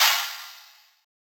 DDK1 OPEN HAT 1.wav